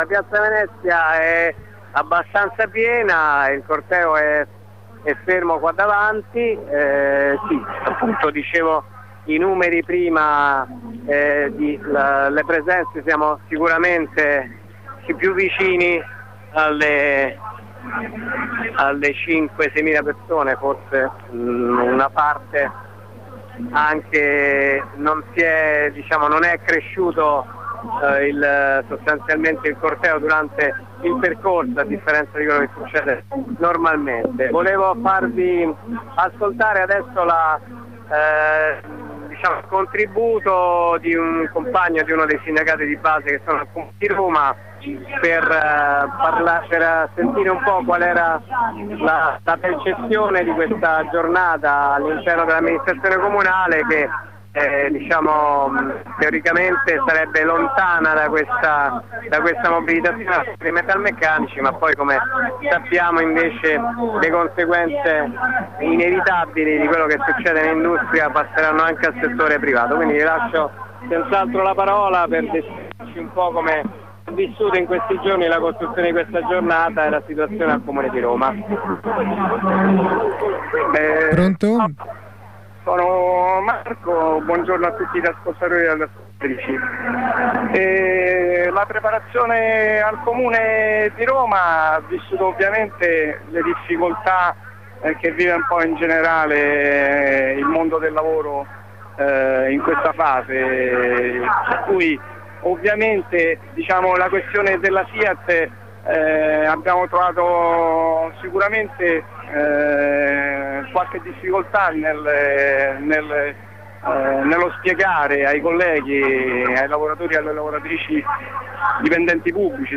A Roma si è concluso il corteo a piazza Venezia, tiriamo le somme con un compagno della redazione e con un lavoratore del comune di Roma